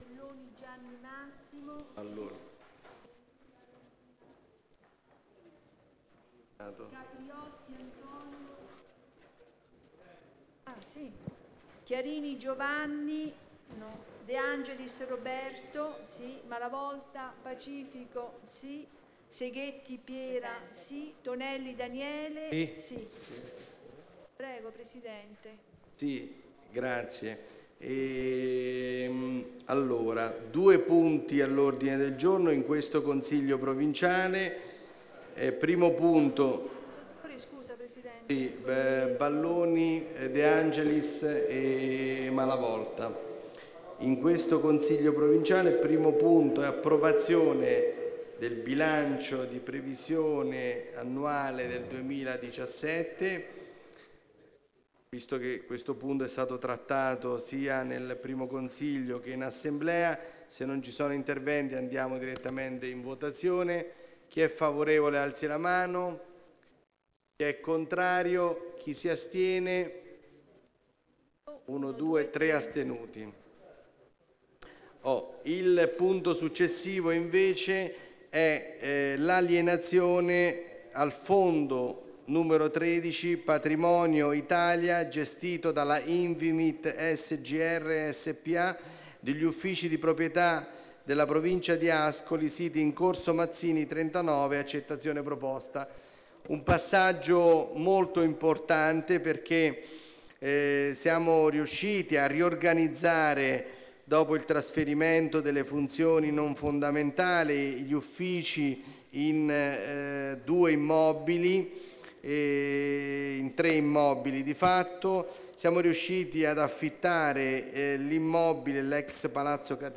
Seduta del consiglio del 21 Novembre 2017 - ore 16,50